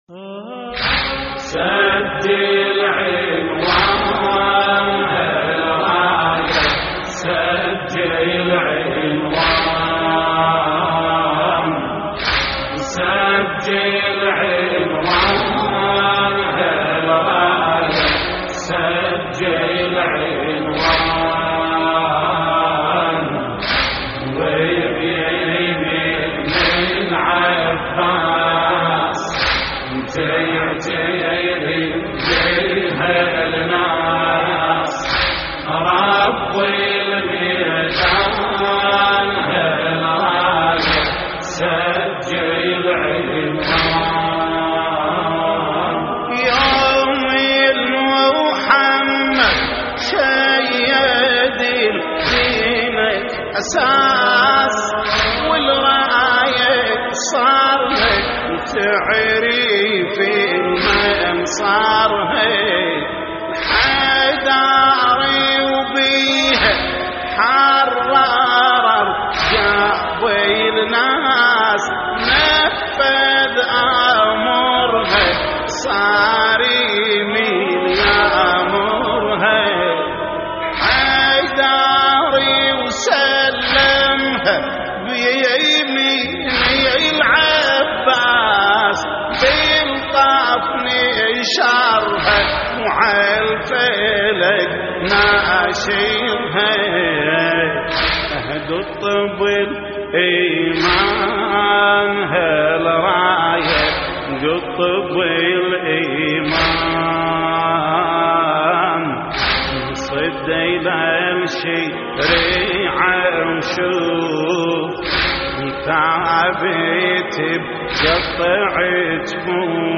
تحميل : سجل عنوان هالرايه وبيمين العباس تعترف بها الناس / الرادود جليل الكربلائي / اللطميات الحسينية / موقع يا حسين
موقع يا حسين : اللطميات الحسينية سجل عنوان هالرايه وبيمين العباس تعترف بها الناس - استديو لحفظ الملف في مجلد خاص اضغط بالزر الأيمن هنا ثم اختر (حفظ الهدف باسم - Save Target As) واختر المكان المناسب